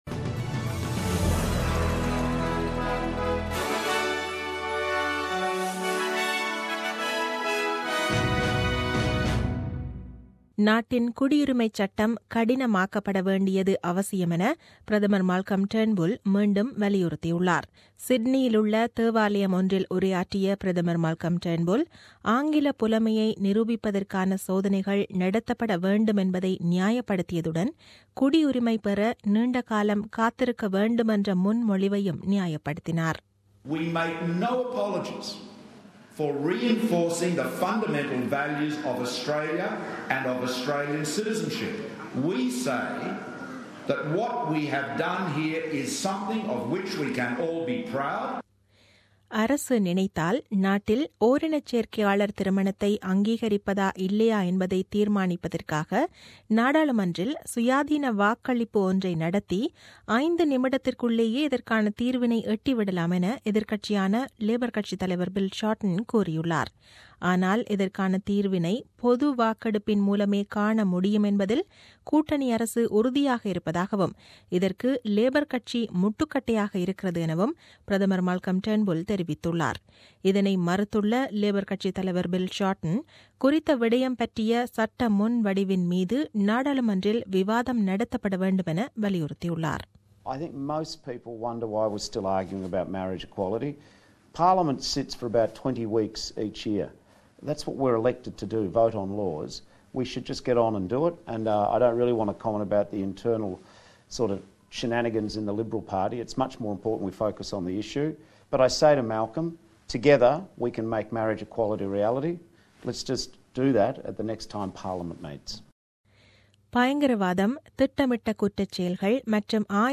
The news bulletin aired on 26 June 2017 at 8pm.